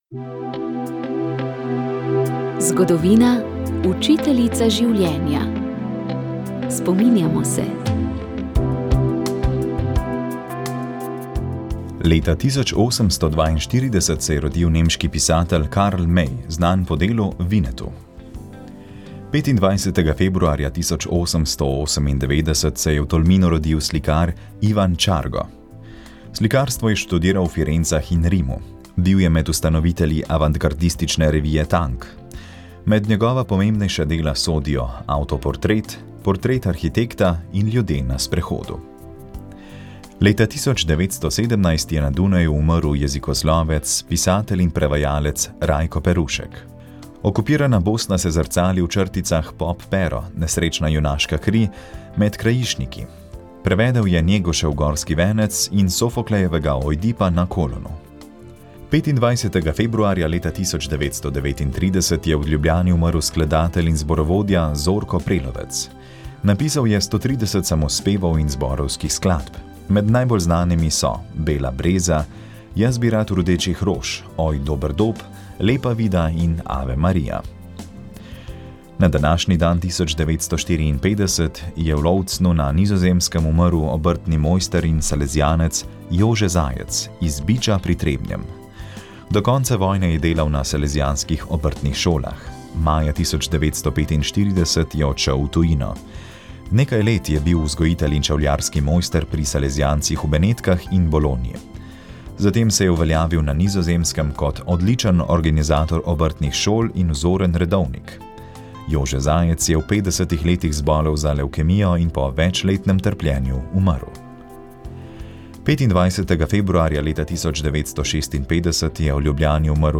V soboto, 8. februarja bodo v Galeriji Družina, v Ljubljani odprli razstavo Umetniki za Karitas. Razstavljena bodo dela jubilejne 30. likovne kolonije, ki se je na Sinjem vrhu nad Ajdovščino odvila pod geslom Mostovi upanja. Prisluhnili boste lahko nekaterim umetnikom in organizatorjem.